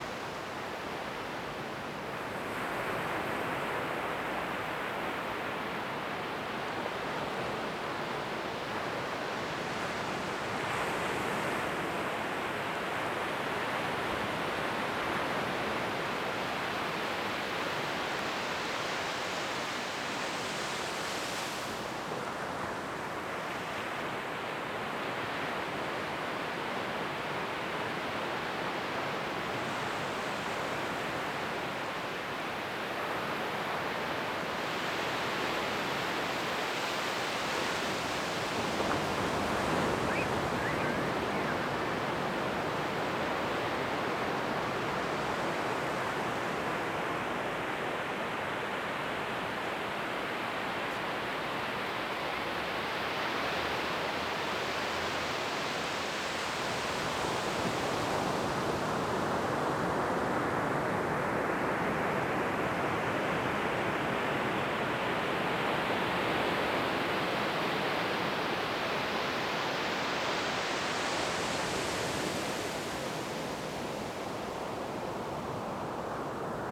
260305_scheveningen_waves
Recorded with zoom H6 XY mic
Natur / Väder